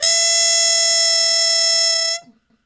Index of /90_sSampleCDs/E-MU Formula 4000 Series Vol. 1 - Hip Hop Nation/Default Folder/Trumpet MuteFX X